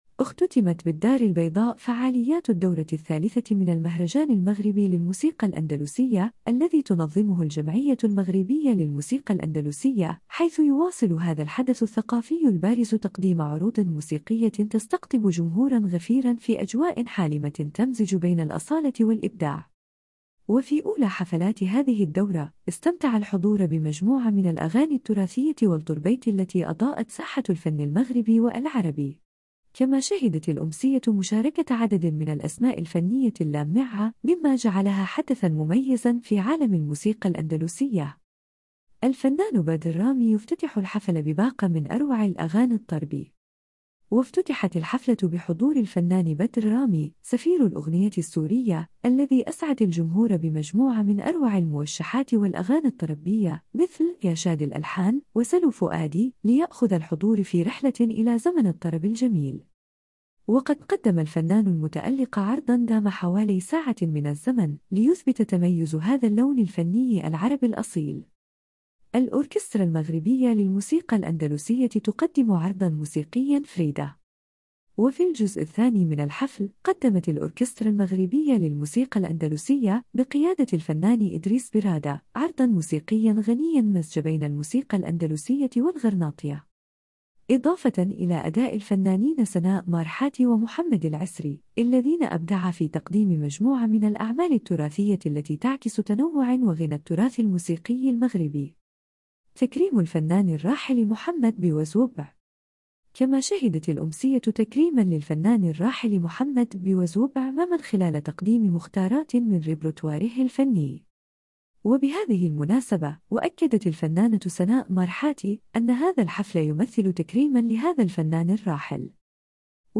وفي أولى حفلات هذه الدورة، استمتع الحضور بمجموعة من الأغاني التراثية والطربية التي أضاءت ساحة الفن المغربي والعربي.